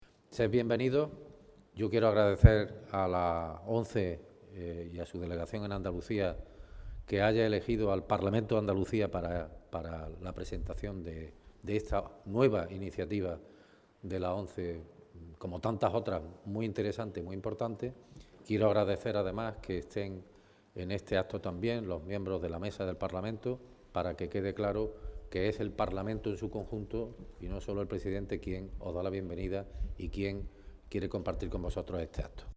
daba la bienvenida al grupo de pequeños escolares a la sede del Parlamento Andaluz, en donde los pequeños leyeron el Manifiesto por la Ilusión (archivo MP3).